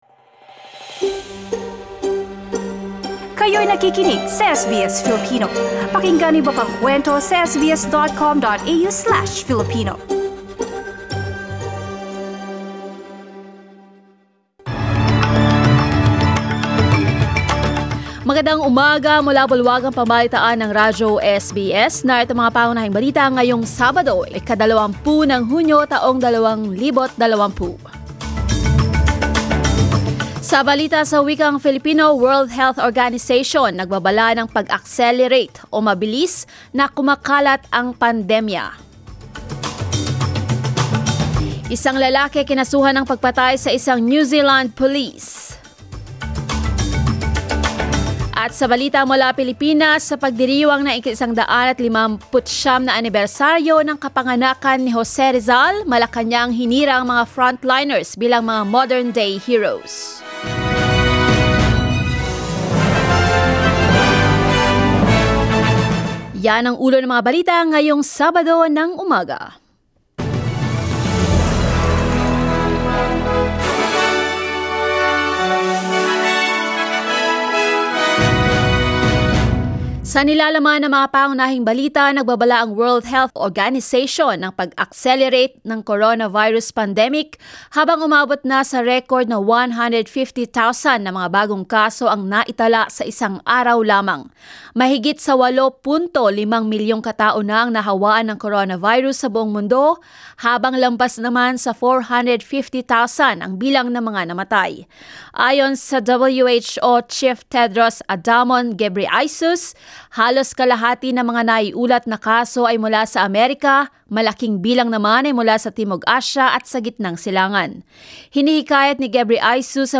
SBS News in Filipino, Saturday 20 June